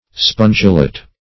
Spongelet \Sponge"let\ (-l[e^]t), n.